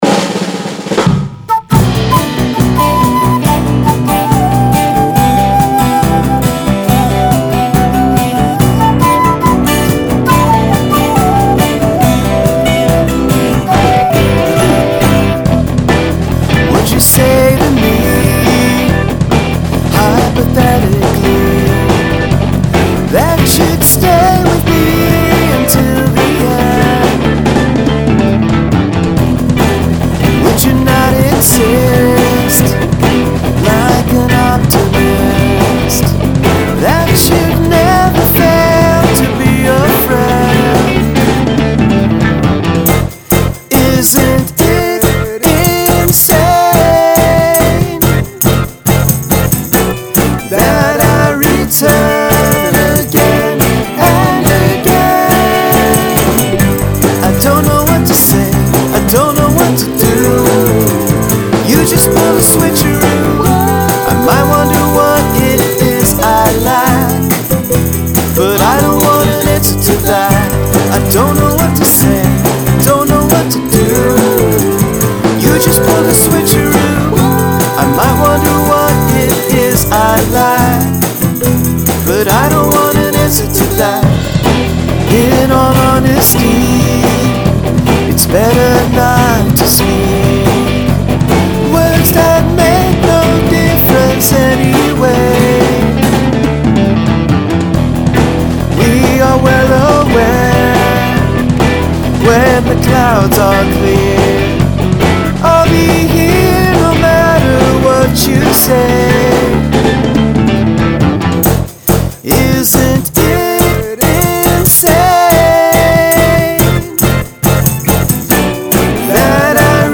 Nice flute intro.